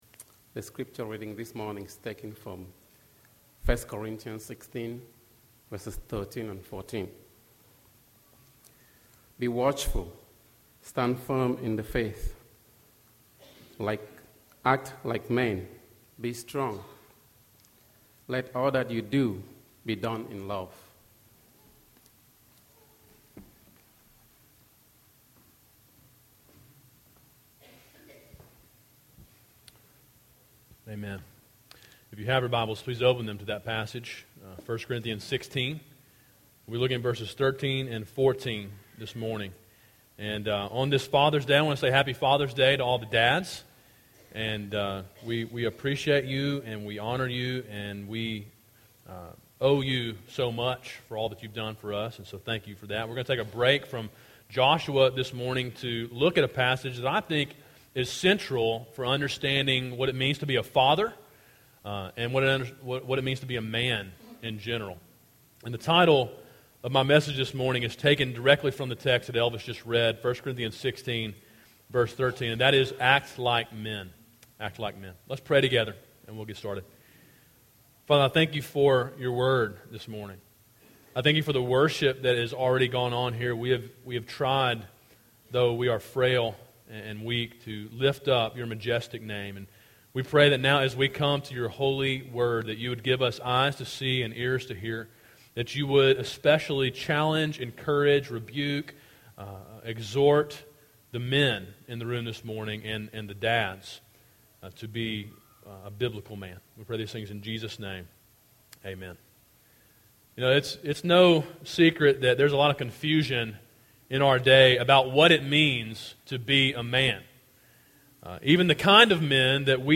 A sermon preached on Father's Day, 6.21.15. Download mp3